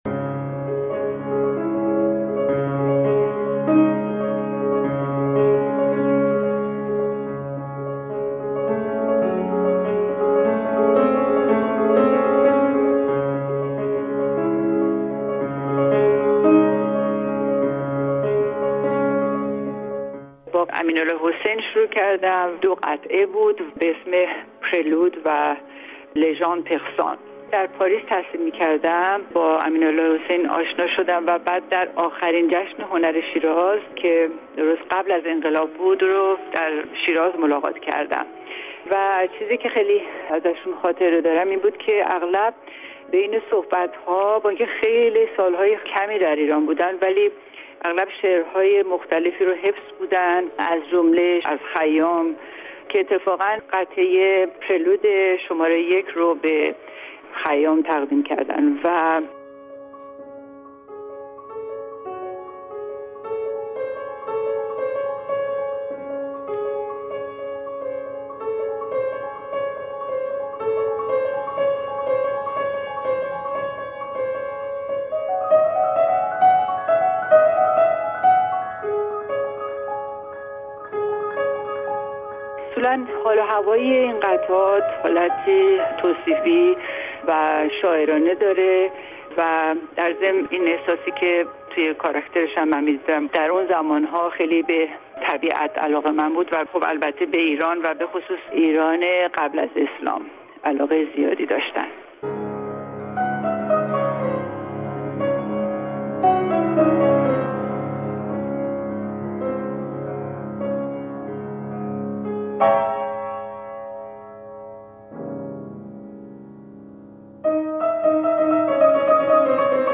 تکنواز پیش کسوت پیانو در گفت و گو با رادیو فردا درباره تازه ترین اجراهایش از قطعات امین الله حسین و علیرضا مشایخی آهنگسازان ایرانی می گوید.